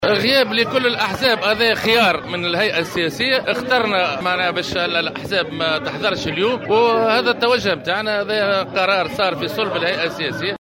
وأضاف في تصريح لـ"الجوهرة أف أم" أن الحزب خيّر عدم دعوة الاحزاب السياسية، مؤكدا أنه قرار تم اتخاذه صلب الهيئة السياسية.